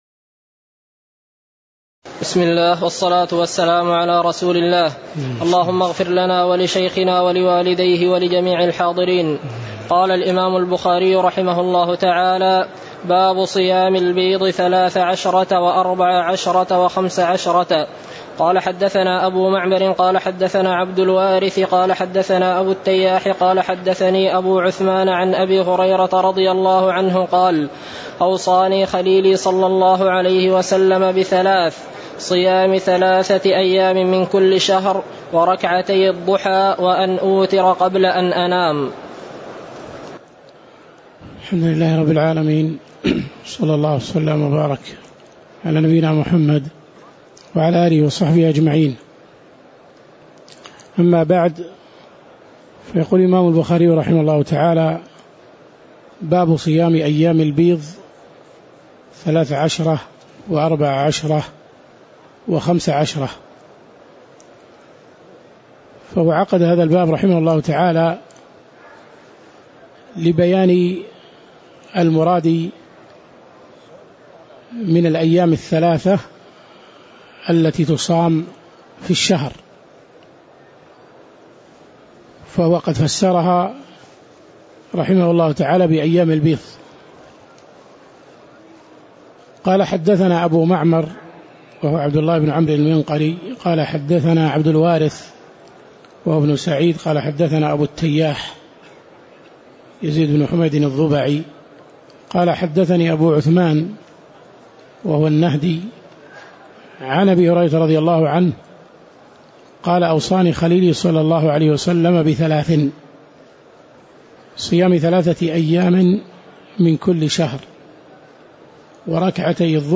تاريخ النشر ١٥ رمضان ١٤٣٨ هـ المكان: المسجد النبوي الشيخ